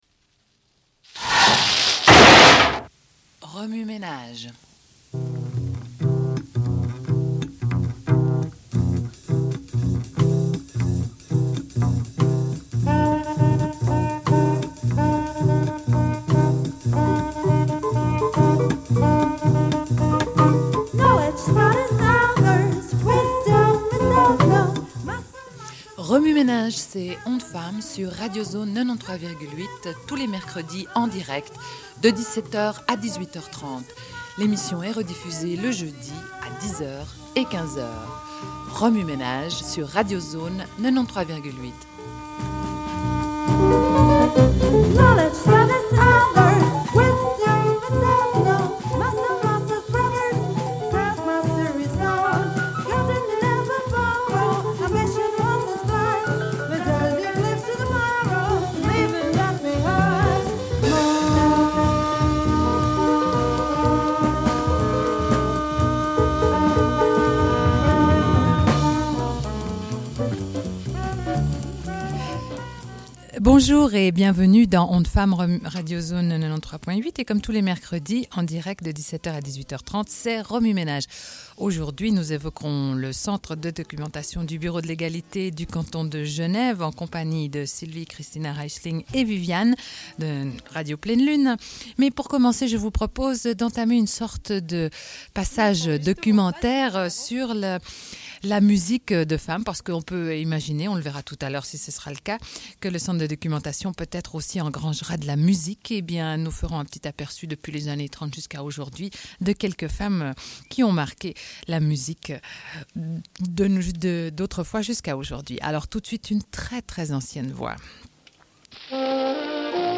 Une cassette audio, face A31:18